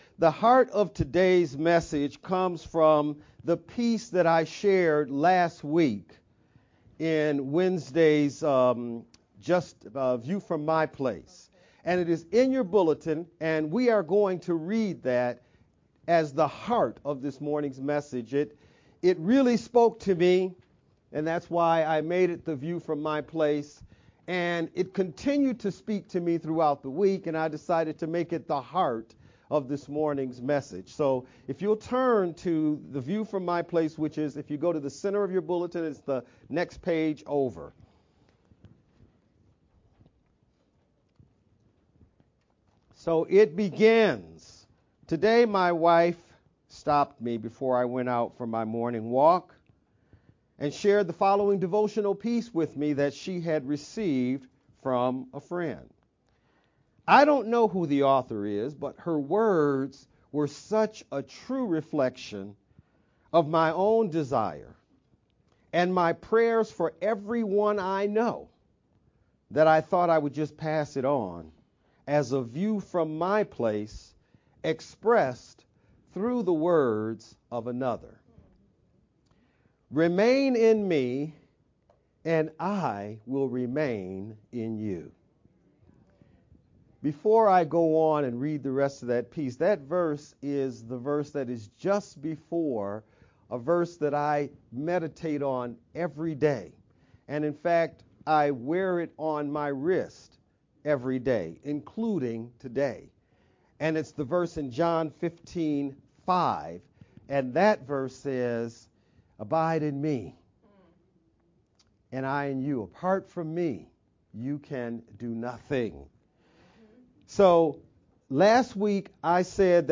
July-16th-Sermon-only-Mp3_Converted-CD.mp3